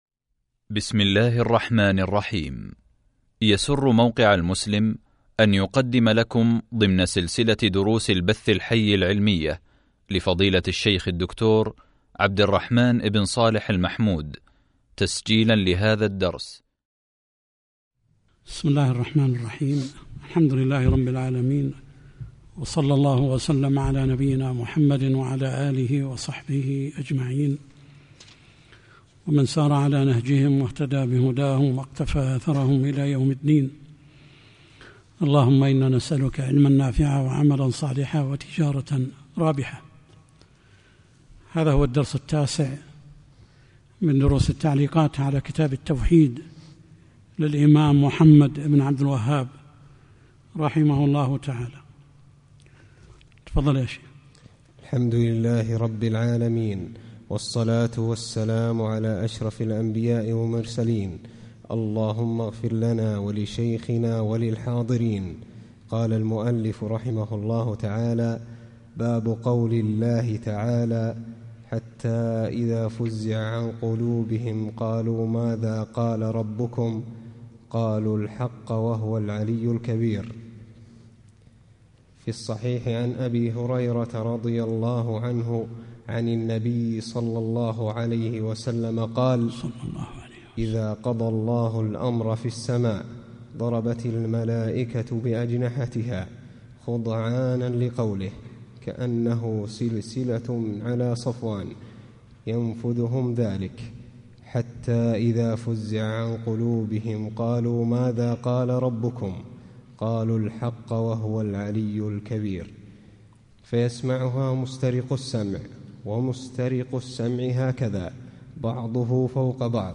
شرح كتاب التوحيد | الدرس 9